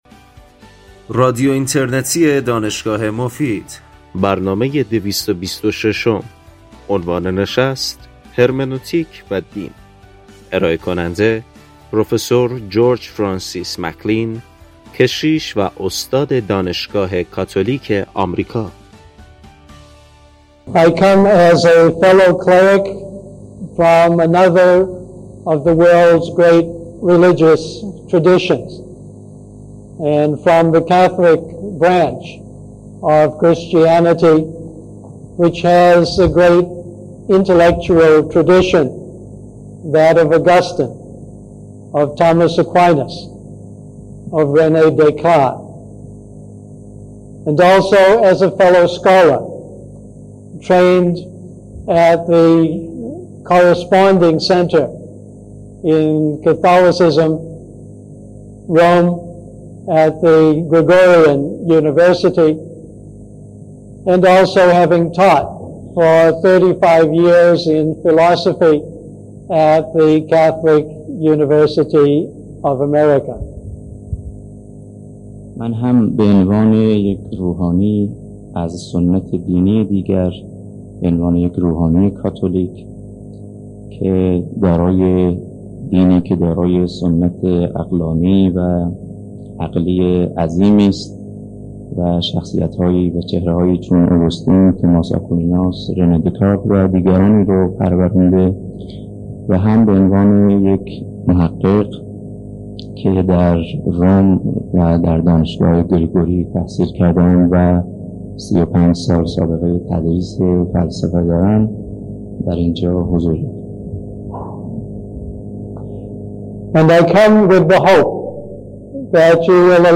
این سخنرانی در سال ۱۳۷۹ و به زبان انگلیسی همراه با ترجمه فارسی ایراد شده است.
ایشان همچنین توجه به ضمیر و قلب مخاطبان پیام الهی و چگونگی عملکرد مبلغان دین، خاصه در باب فهم و انتقال پیام دین مباحثی را ارائه می کنند. بخش پایانی برنامه به پرسش و پاسخ اختصاص دارد.